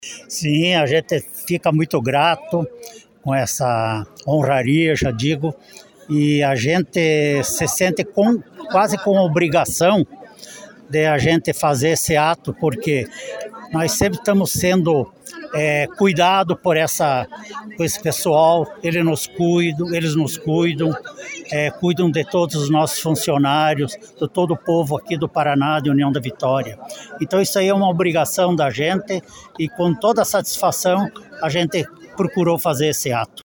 Em União da Vitória, a data foi celebrada com uma solenidade especial realizada nas dependências do 27º Batalhão de Polícia Militar, reunindo autoridades civis e militares, além de familiares e convidados.